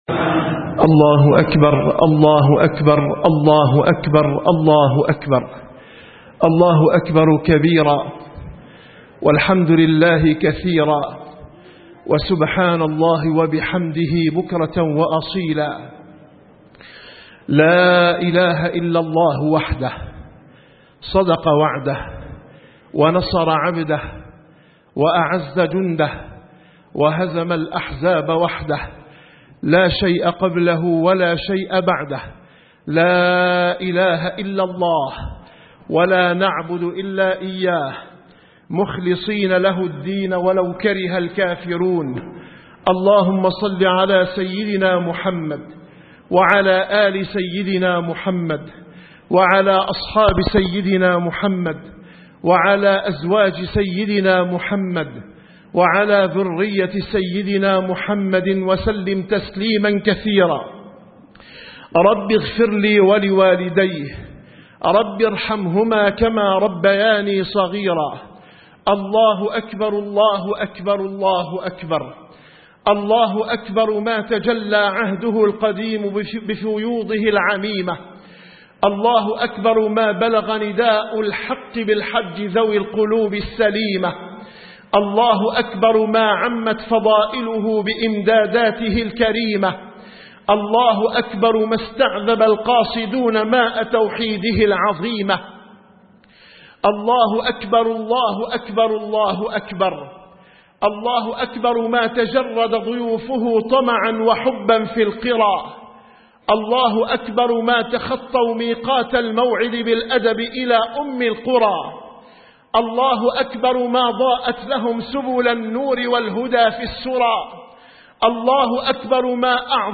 خطبة الأضحى